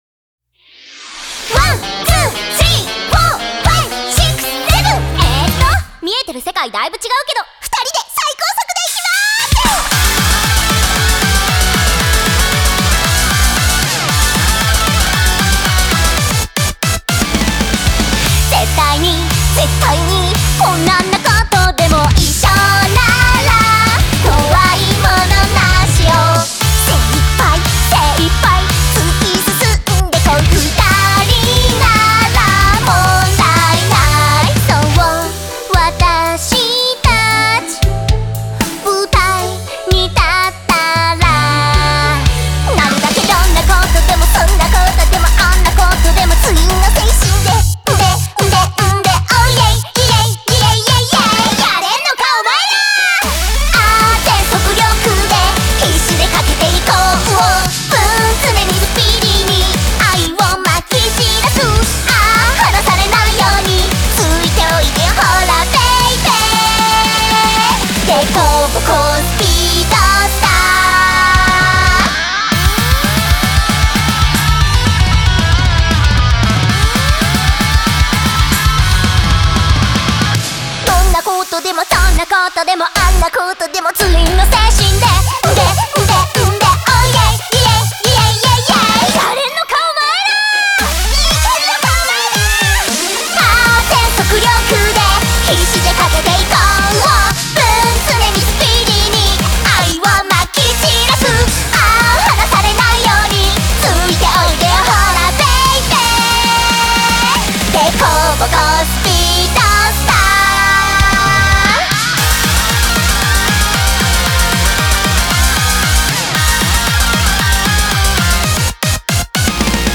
BPM230
Audio QualityPerfect (High Quality)
Do you like fast idol songs?